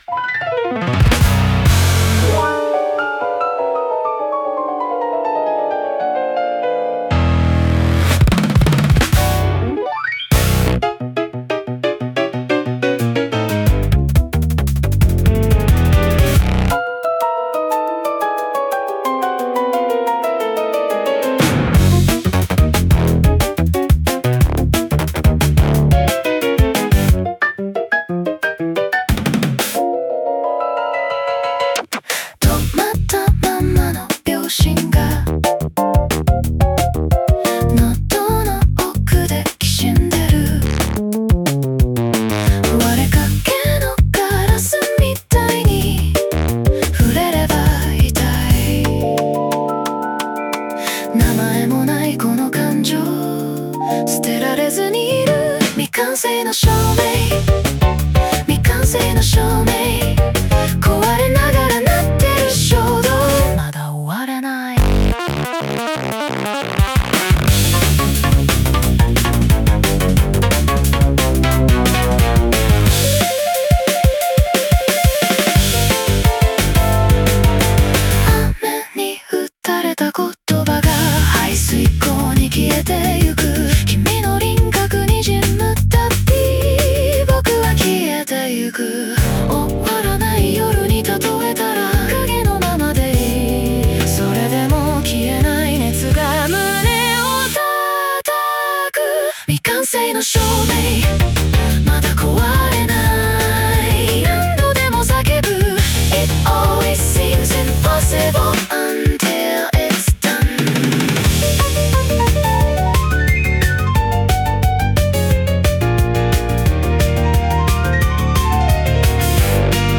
歌ものフリー素材 bgm音楽 無料ダウンロード 商用・非商用ともに登録不要で安心してご利用いただけます。